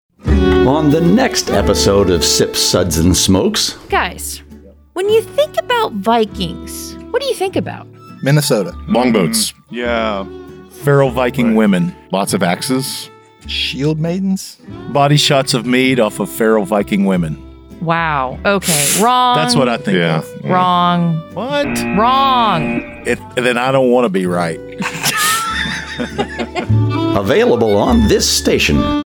SUDS403_Einstök_Promo.mp3 735k
192kbps Mono